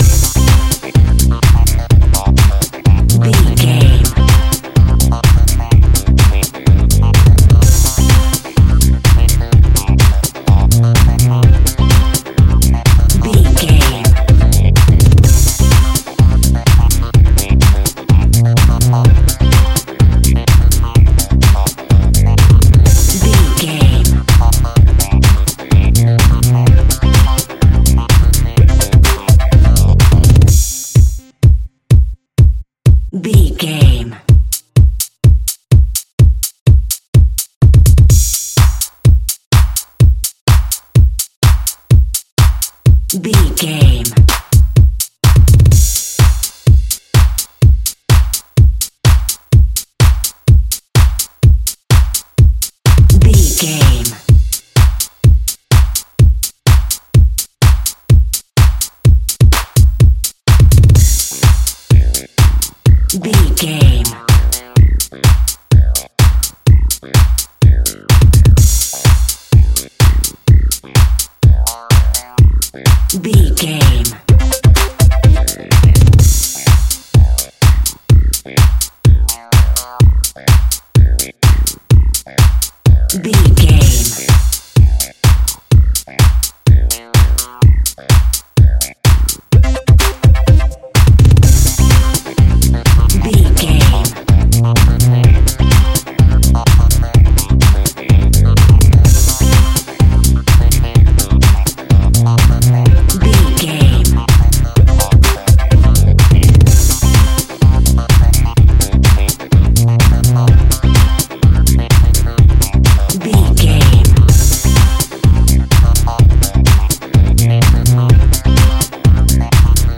Fast paced
Aeolian/Minor
dark
groovy
uplifting
driving
energetic
drums
bass guitar
synthesiser
electro house
synth pop
drum machine